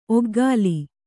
♪ oggāli